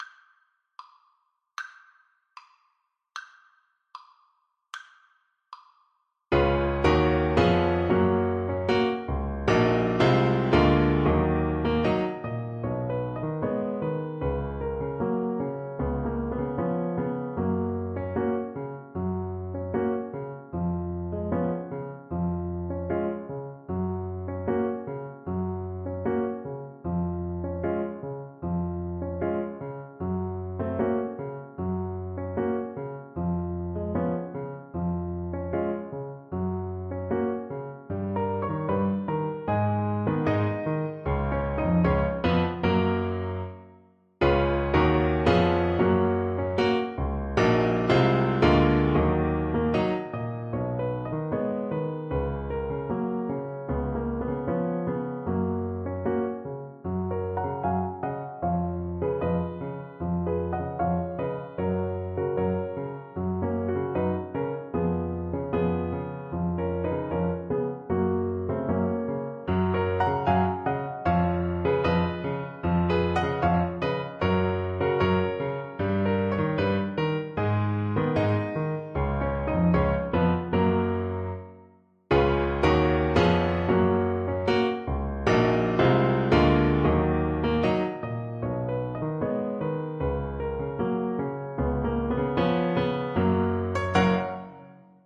Alto Saxophone version
Play (or use space bar on your keyboard) Pause Music Playalong - Piano Accompaniment Playalong Band Accompaniment not yet available transpose reset tempo print settings full screen
2/4 (View more 2/4 Music)
Tempo di habanera = c. 76
Gb major (Sounding Pitch) Eb major (Alto Saxophone in Eb) (View more Gb major Music for Saxophone )
Classical (View more Classical Saxophone Music)
Mexican